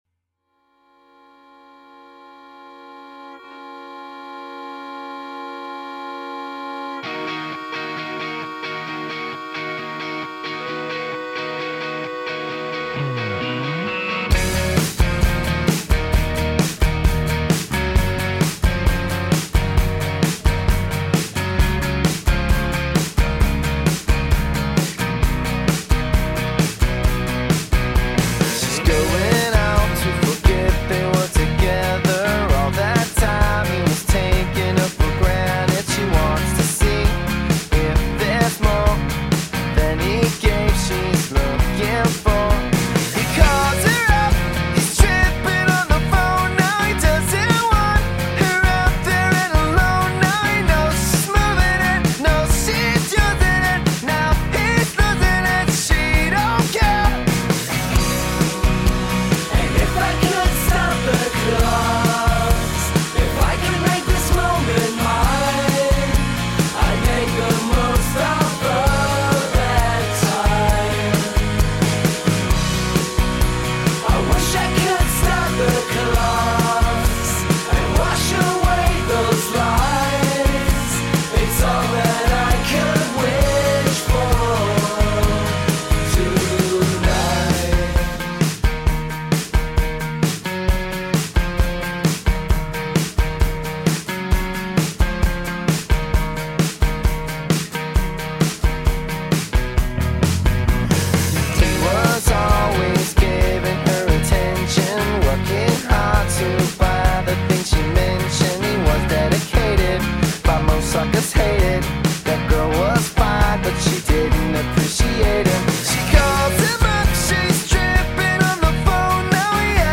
Bootleg